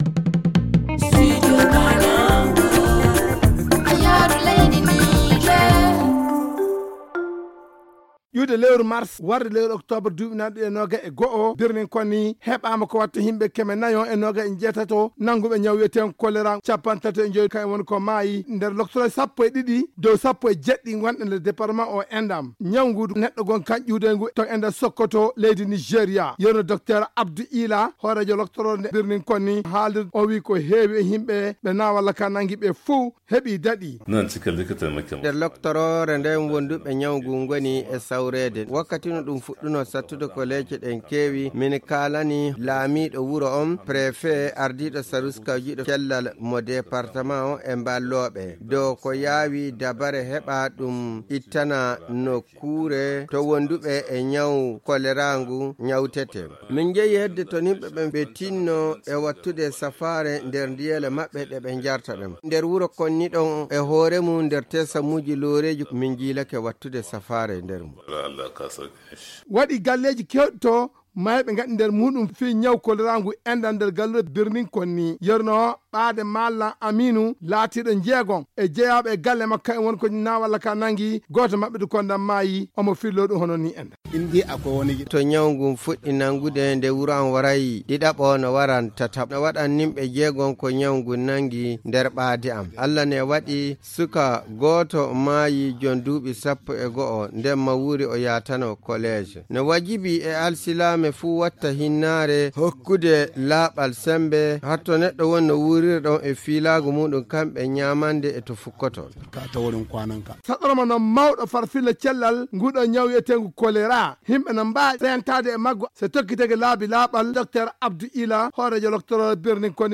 Le magazine en français